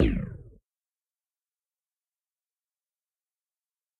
plunge.ogg